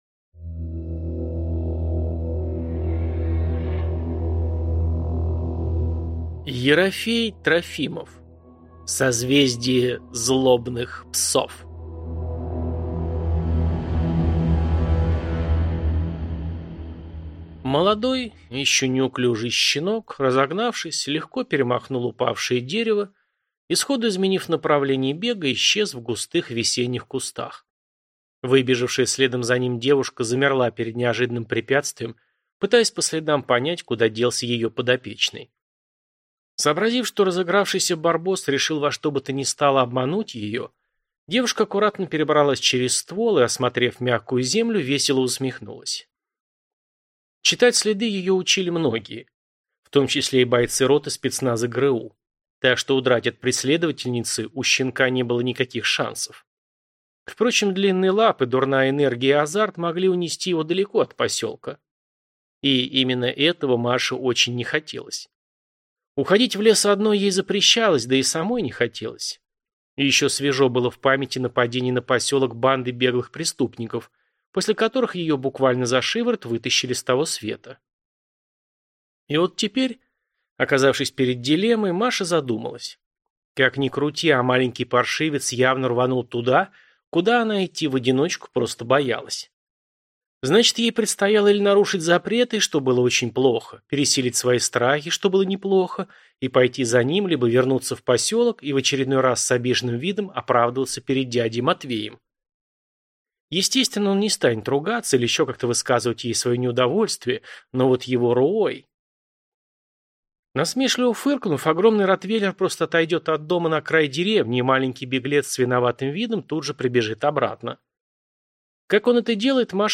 Аудиокнига Созвездие злобных псов | Библиотека аудиокниг